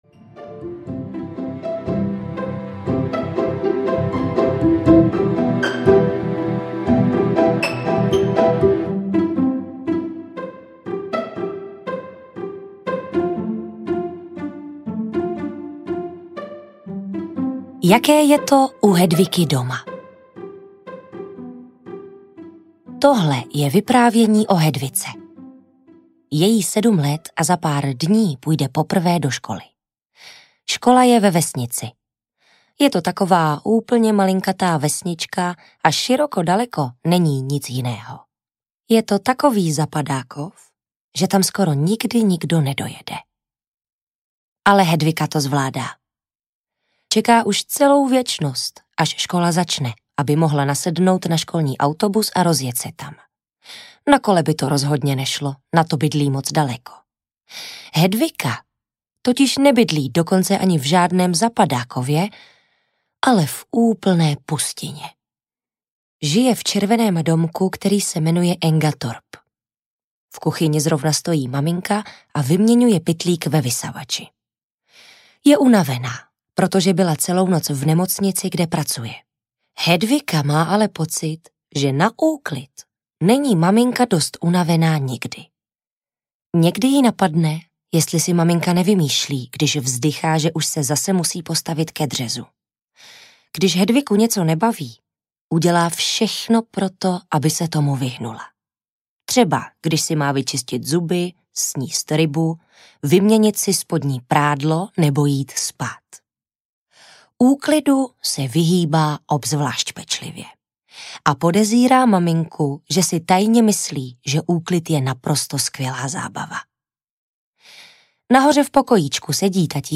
Ve škole se dějou věci audiokniha
Ukázka z knihy